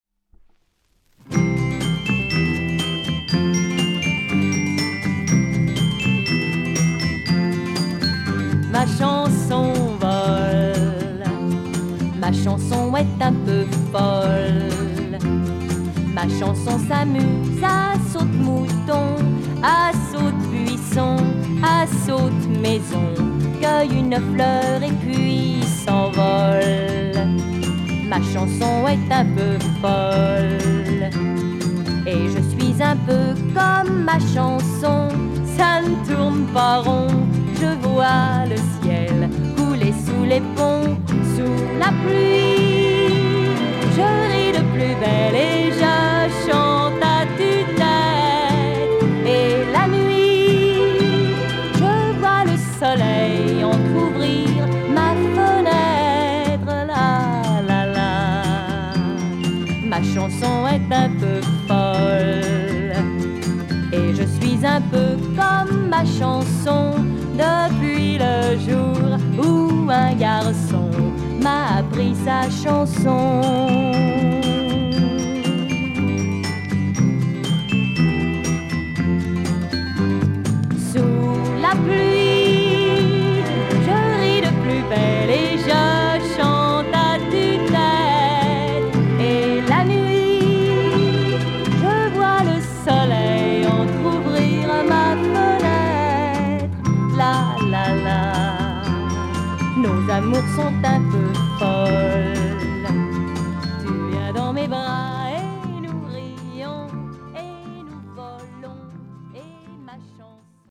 French female Pop chanson single